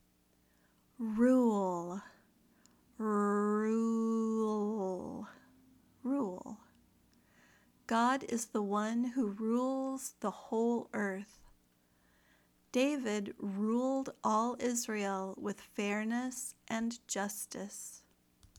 /ruːl/ (verb)